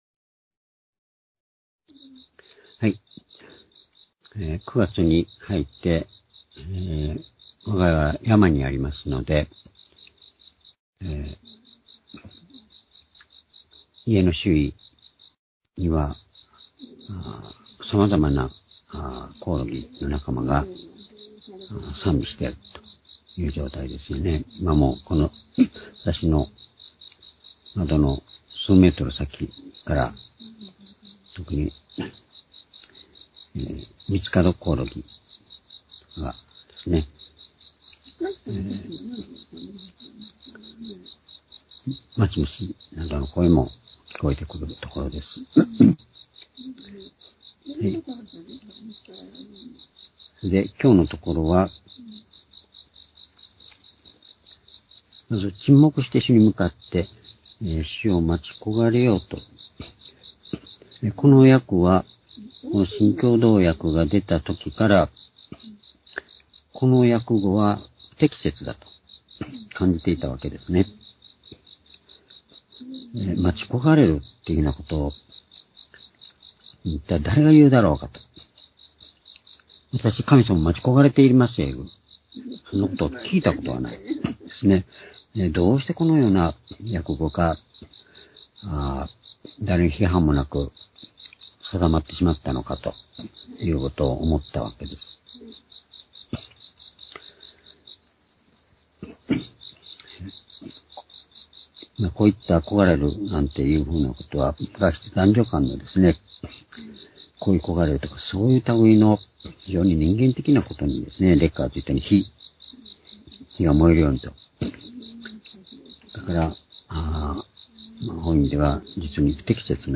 （主日・夕拝）礼拝日時 2023年9月5日（夕拝） 聖書講話箇所 「地を受け継ぐ」 詩編37の7-11 ※視聴できない場合は をクリックしてください。